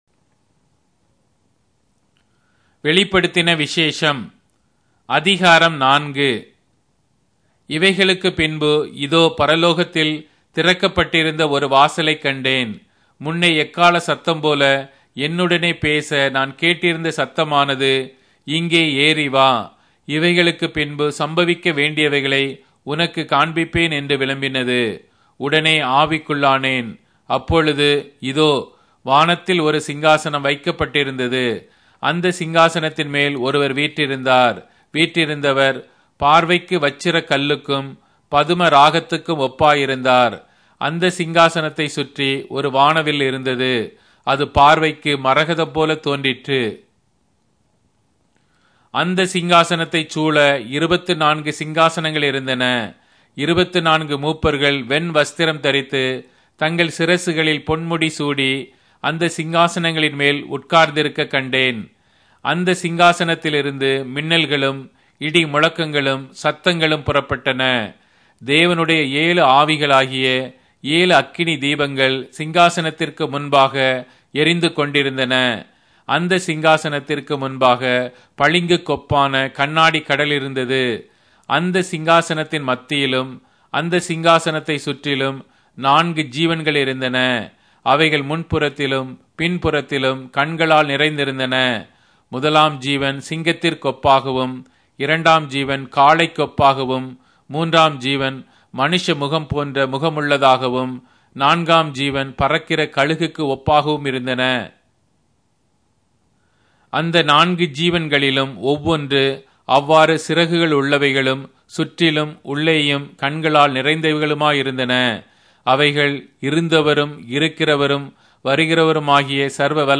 Tamil Audio Bible - Revelation 9 in Lxxen bible version